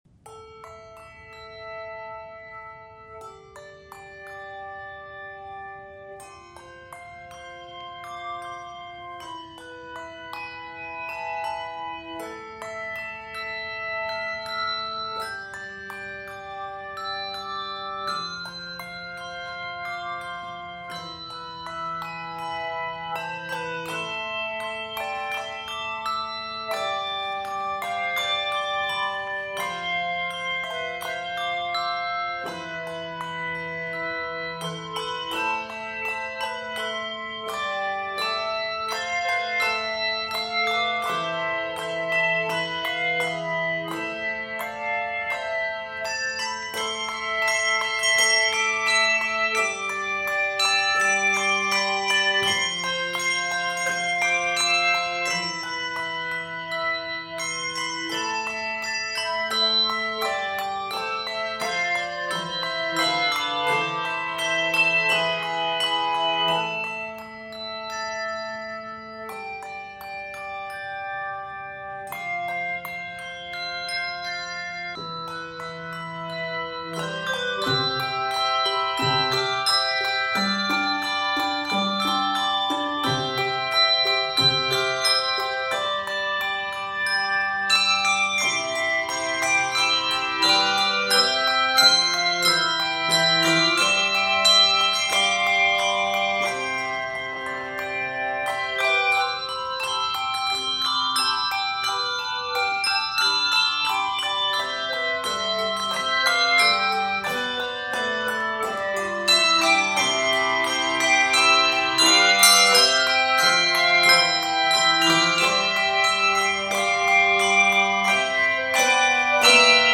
This engaging and contrasting medley
Keys of a minor and C Major.